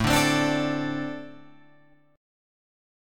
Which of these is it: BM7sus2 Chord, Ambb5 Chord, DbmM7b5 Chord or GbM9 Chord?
Ambb5 Chord